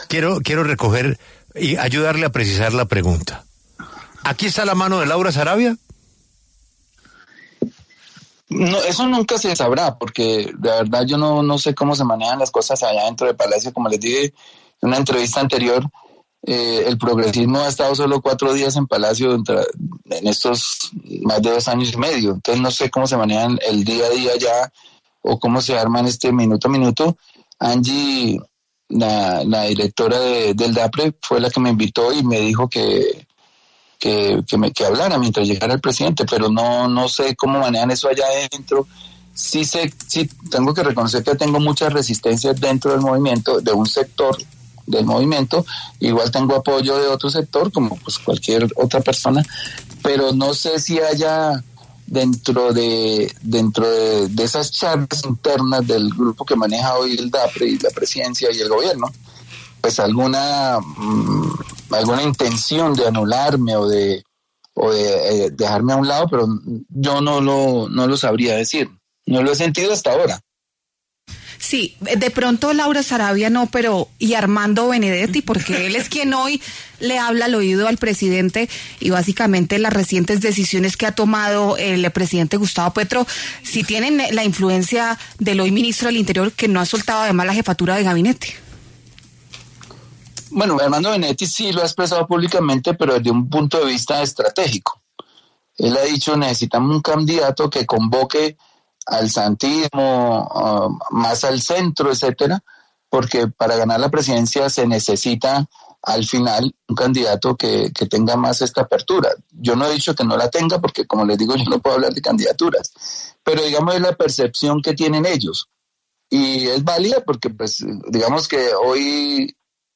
El director del Departamento de Prosperidad Social, Gustavo Bolívar, quien saldrá de su cargo el 16 de mayo, conversó con La W a propósito del regaño que le dio el presidente Gustavo Petro al asegurar que “los que renunciaron, renunciaron”.
Reviva la entrevista completa con Gustavo Bolívar a continuación